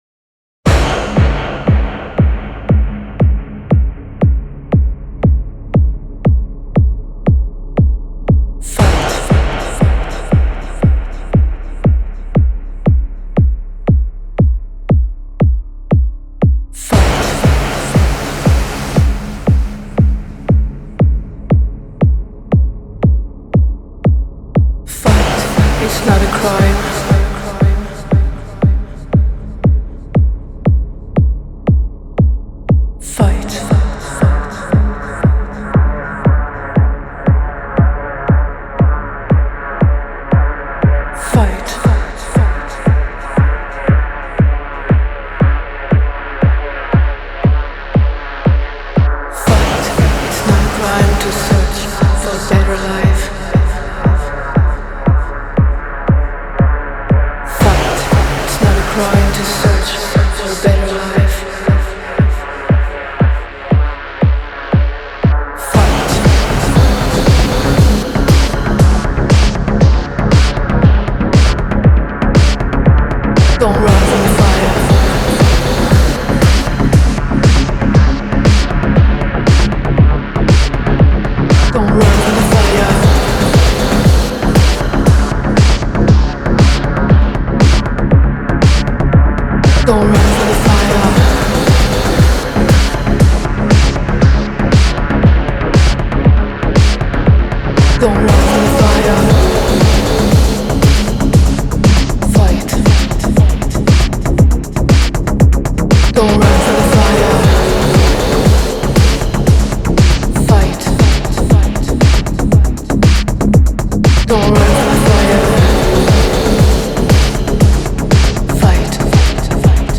EP